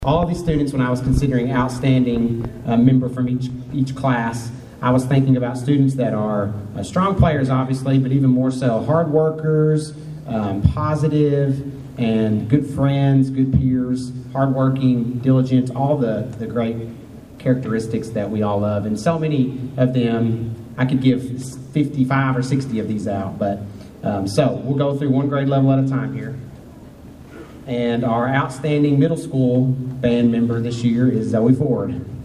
The Caldwell County High School Band of Pride held the end of the Band Banquet Friday night beginning in the high school cafeteria.